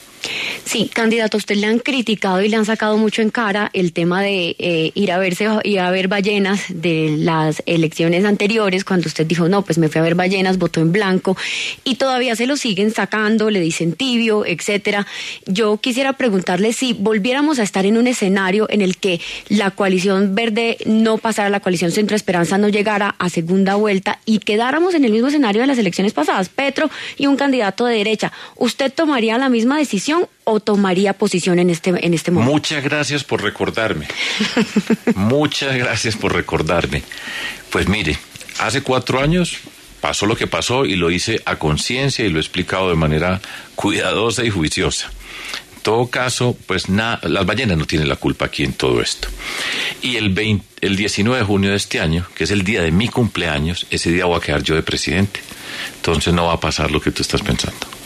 En diálogo con la W Radio, el precandidato presidencial Sergio Fajardo habló sobre diferentes temas de cara a la recta final de las elecciones que se celebrarán el 13 de marzo y decidirá si él será el candidato único de la Coalición Centro Esperanza.